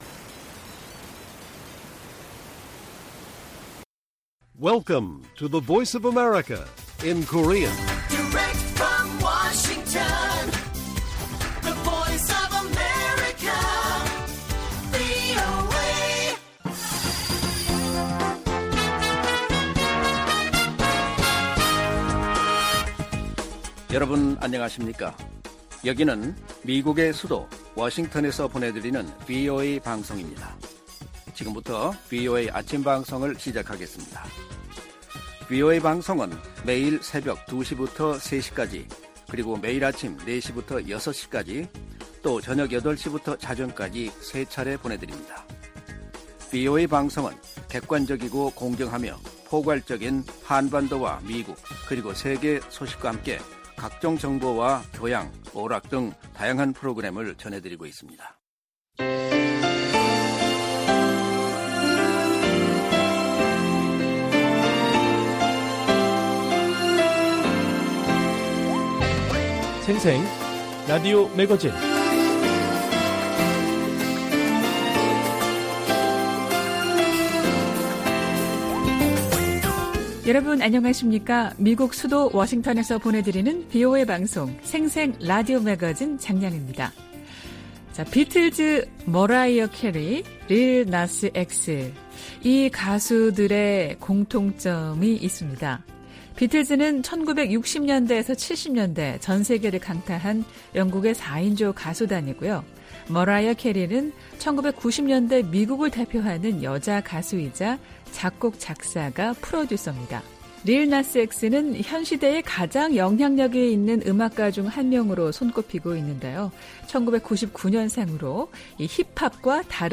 VOA 한국어 방송의 일요일 오전 프로그램 1부입니다.